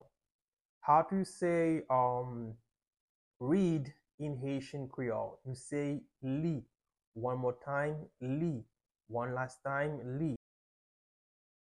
Pronunciation:
11.How-to-say-Read-in-Haitian-Creole-–-Li-with-pronunciation.mp3